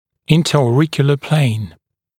[ˌɪntəɔː’rɪkjələ pleɪn] [-kju-][ˌинтэо:’рикйэлэ плэйн] [-кйу-]межушная плоскость